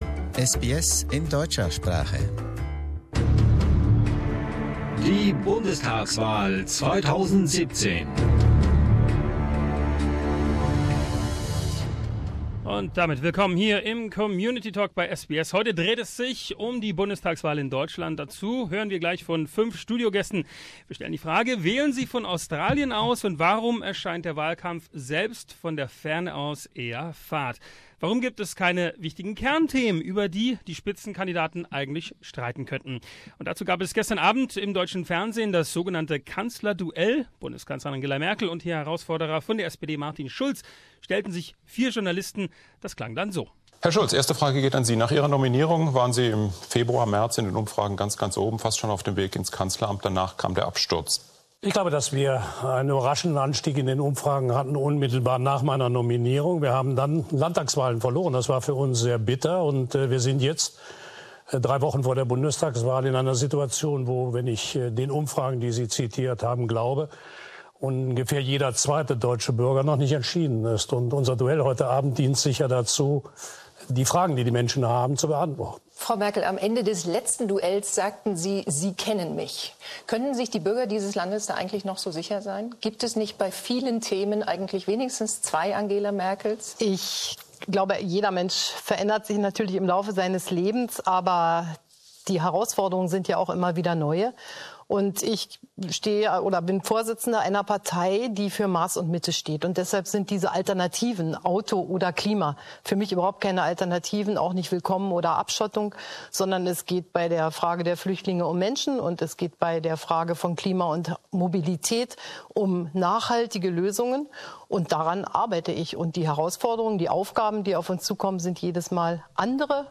Listen to SBS German's community talk ahead of the German federal election. Our five guests are still passionate about the political events in Berlin